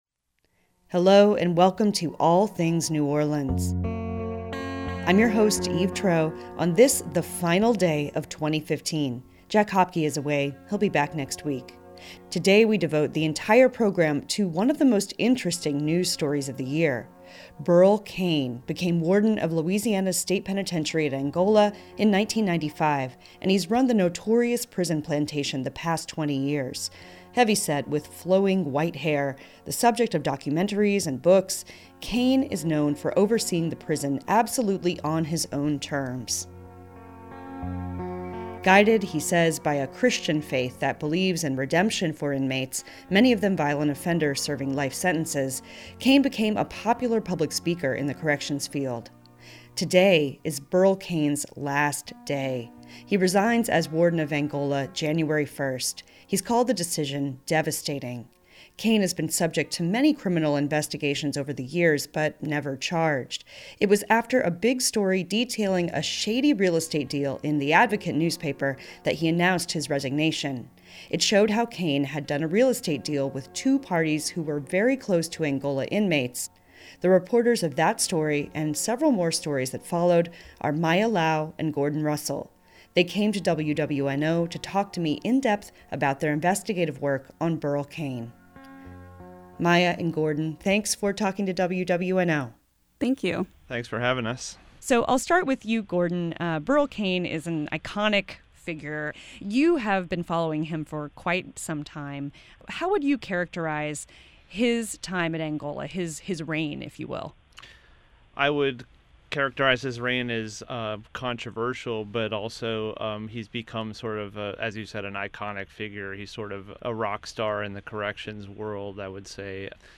Ending The Reign Of Burl Cain: An In-Depth Interview